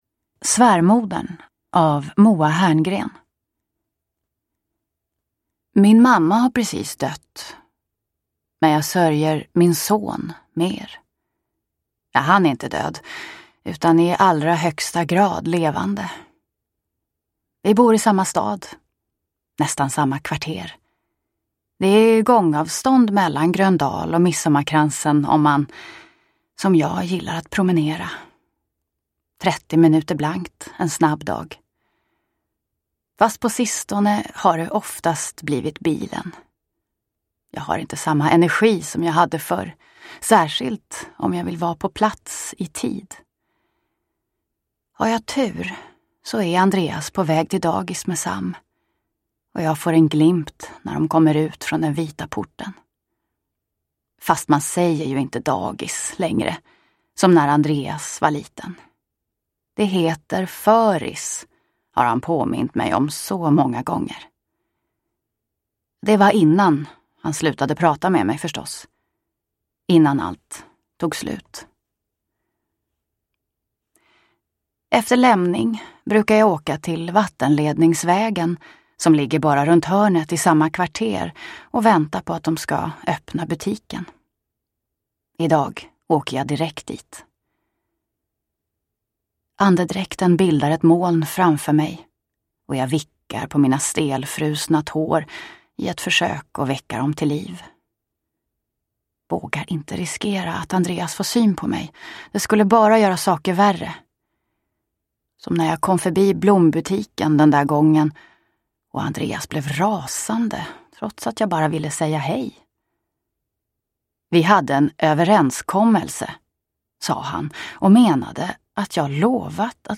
Svärmodern – Ljudbok – Laddas ner